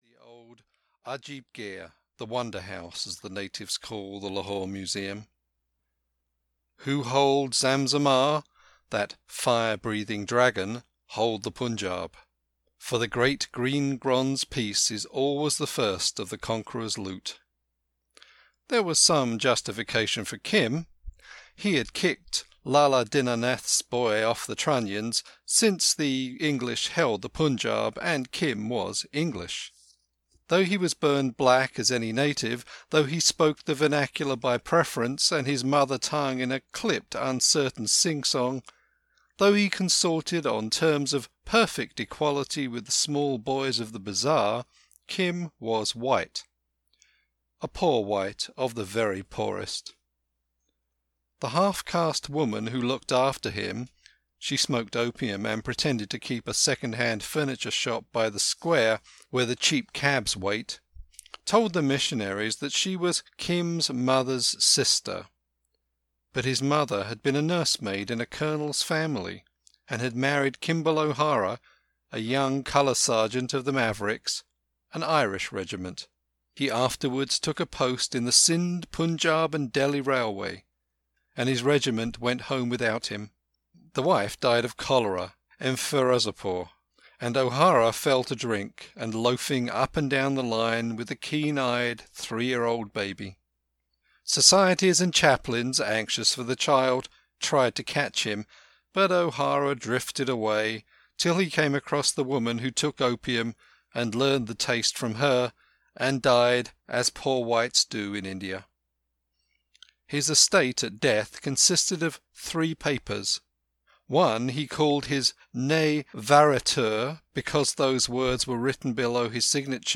Kim (EN) audiokniha
Ukázka z knihy